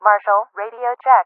MARSHAL-RadioCheck.ogg